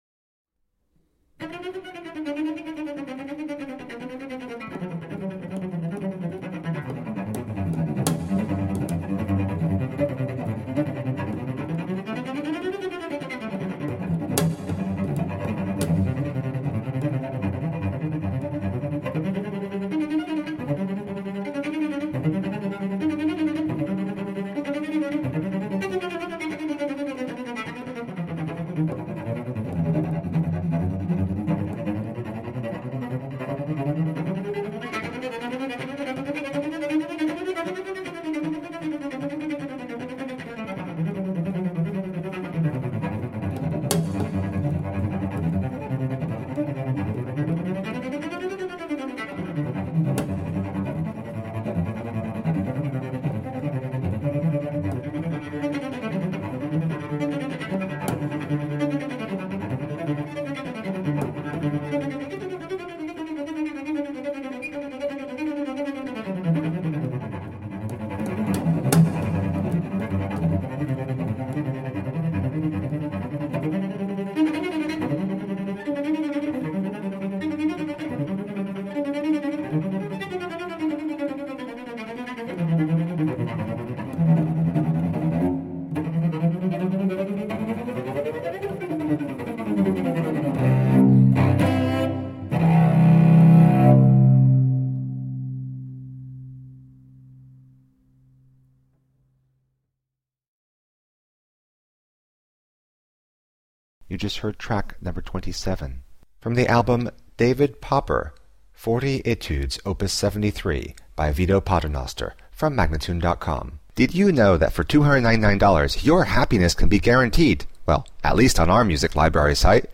Tagged as: Classical, Romantic Era, Instrumental, Cello